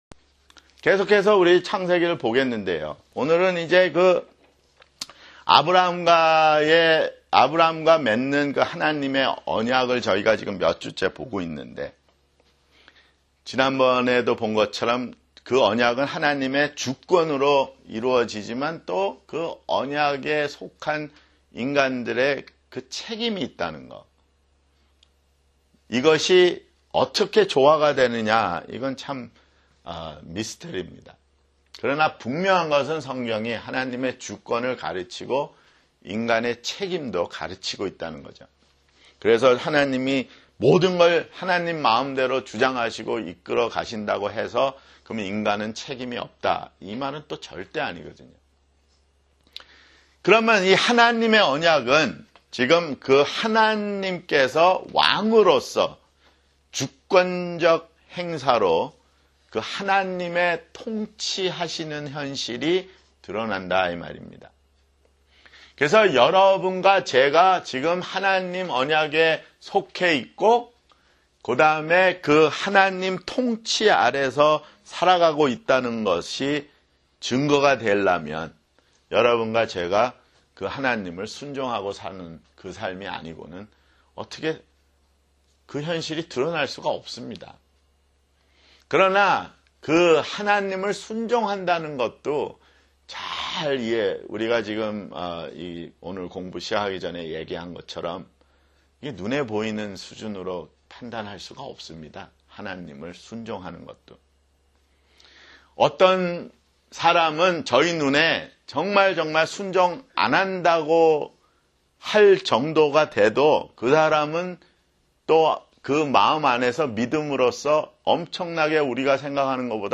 [성경공부] 창세기 (43)